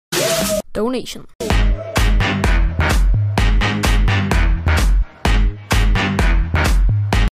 Музыка для донатов на стриме